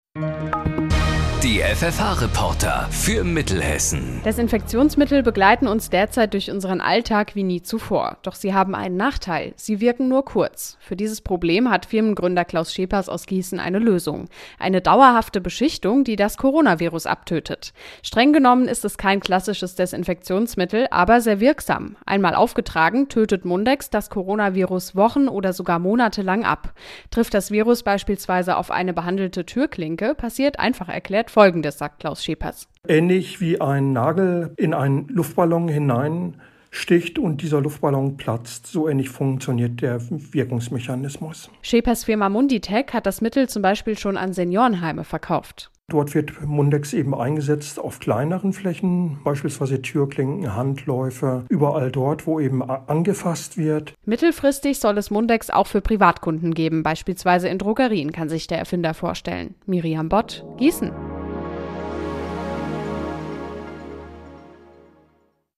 Am 27.04.2020 hat der hessische Radiosender Hit Radio FFH Mundex in einem Beitrag vorgestellt.